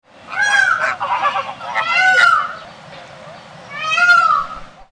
Peacock sound